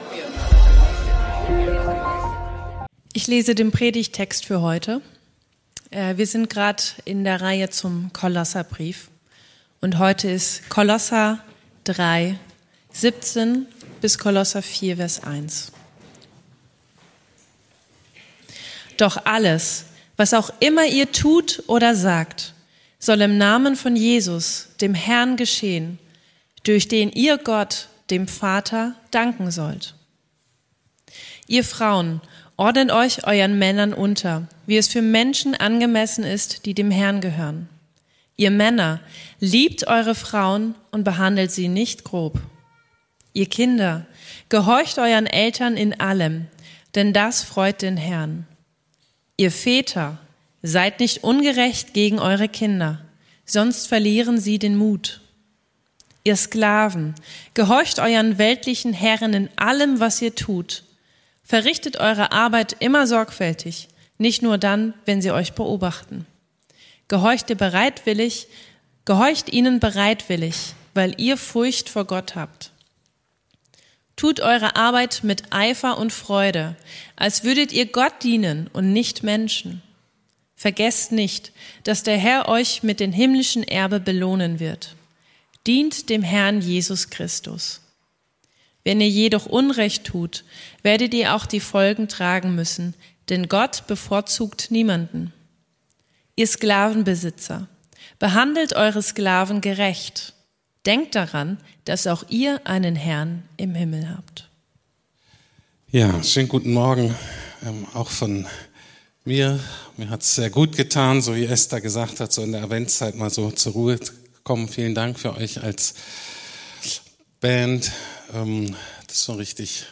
Kolosserbrief - Nachfolge in Familie und Beruf ~ Predigten der LUKAS GEMEINDE Podcast